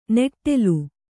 ♪ neṭṭelu